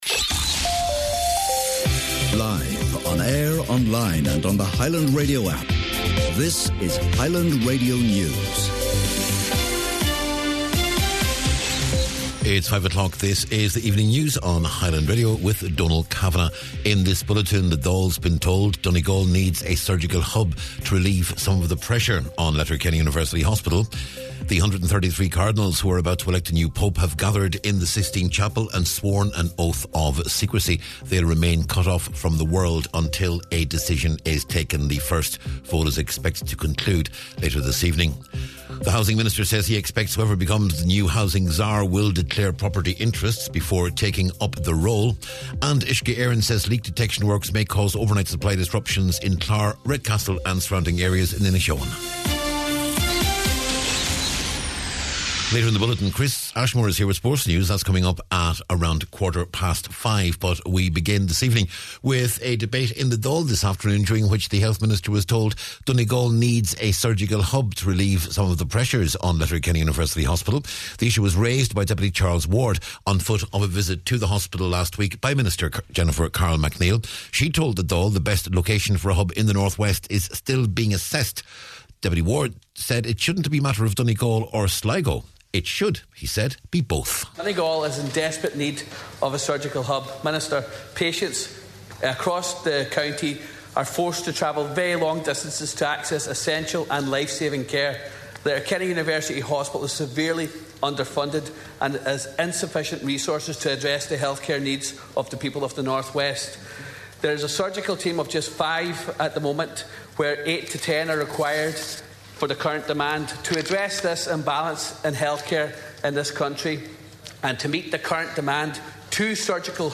News, Sport and Obituaries on Wednesday May 7th